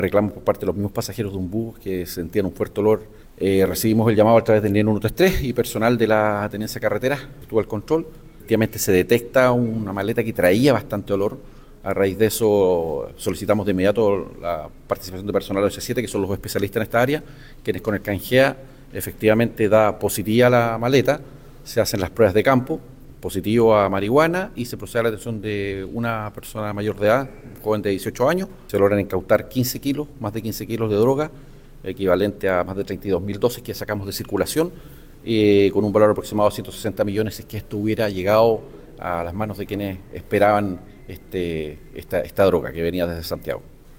Los hechos se dieron el pasado viernes, donde pasajeros alertaron a las autoridades a través del nivel 133 por un fuerte olor que emanaba dentro el bus, lo que desató un procedimiento policial que contó con la participación del can detector de droga Gea, como explicó el Coronel Miguel Valenzuela, Prefecto de Carabineros en Osorno.